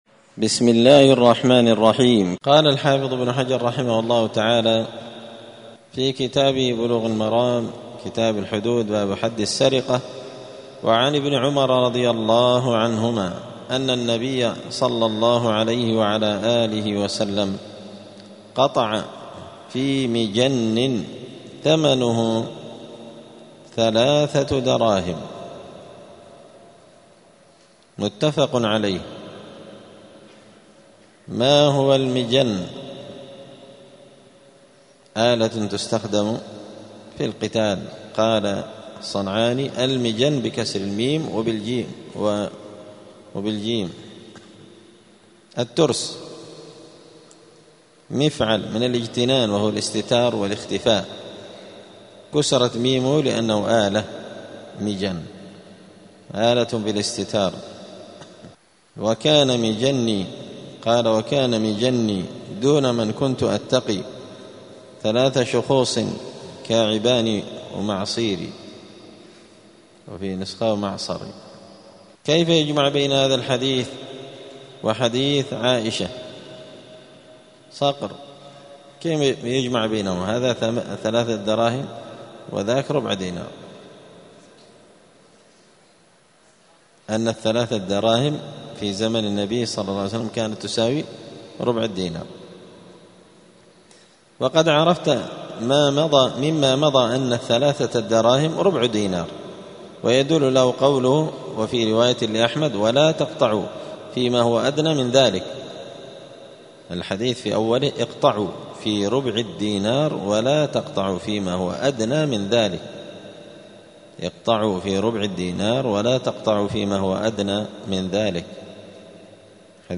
*الدرس العشرون (20) {باب حد السرقة الشفاعة في الحدود}*